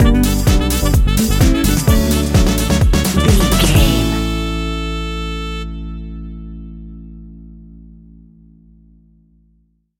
Tight Funky Electro Music Groove Stinger.
Ionian/Major
groovy
uplifting
bouncy
cheerful/happy
electric guitar
horns
drums
bass guitar
saxophone
disco
synth
upbeat